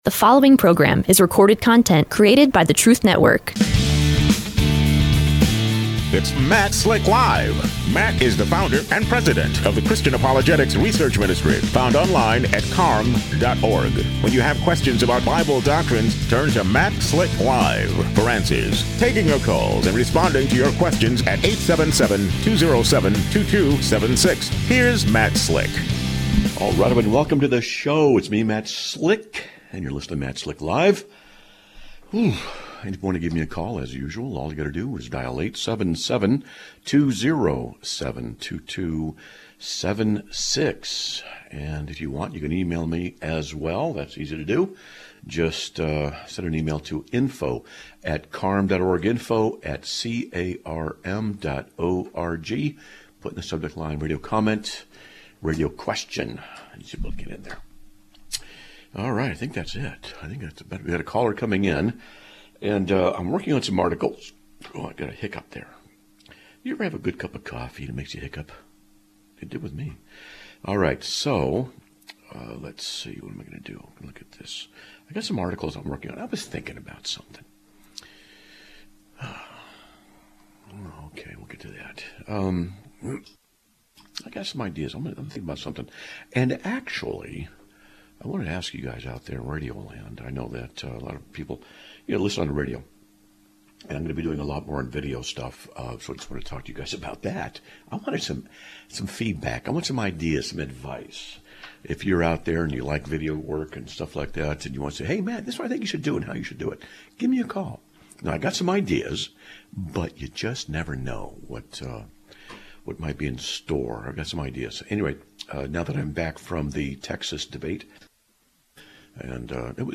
Live Broadcast of 02/10/2026